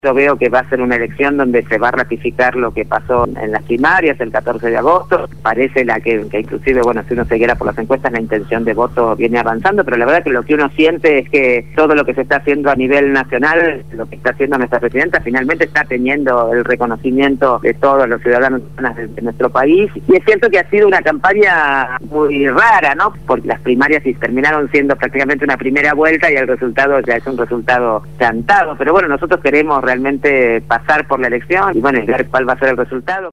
Gabriela Alegre, Legisladora porteña del Frente Para la Victoria (FPV) quien continuará su mandato a partir del próximo 10 de diciembre al haber sido electa el 10 de julio pasado,  habló esta mañana en el programa Punto de Partida de Radio Gráfica FM 89.3